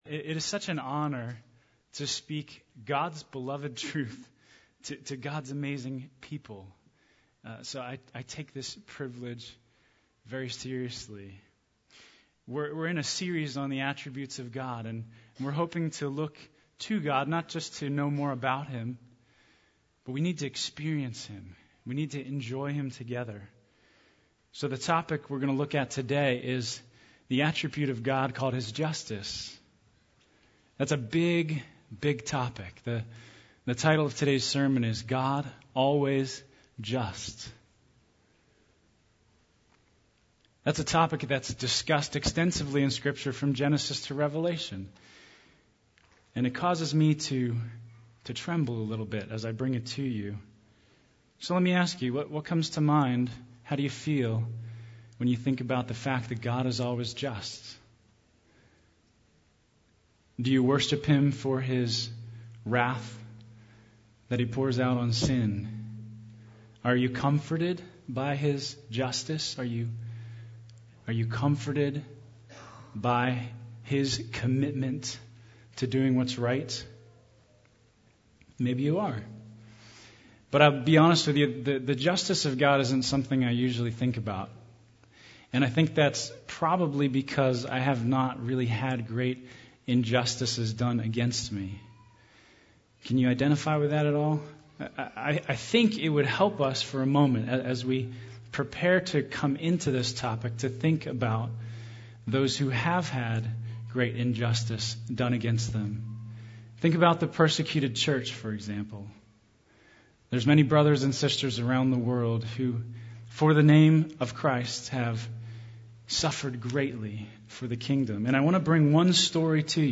Christ is the only hope for all humanity before God the righteous judge. NOTE: The recording equipment malfunctioned right around the 36 minute mark of this recording and 20-30 seconds of audio was lost.